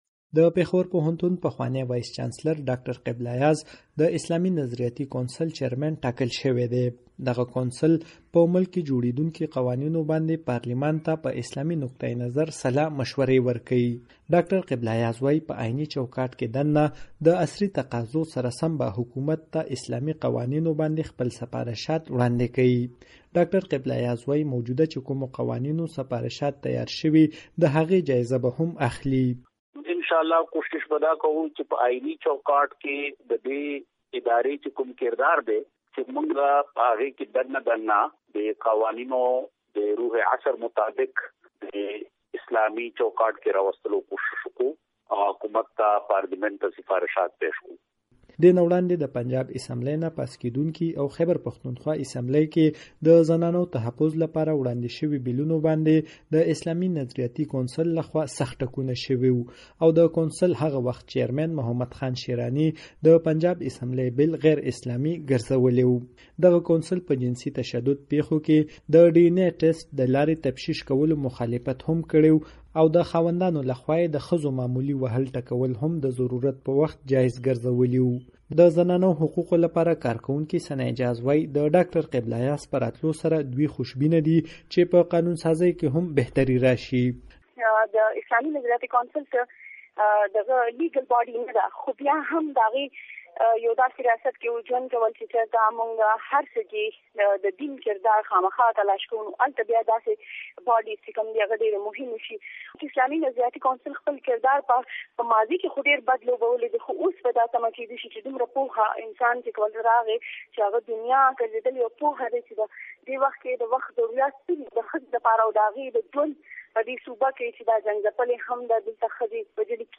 ډاکټر قبله ایاز مرکه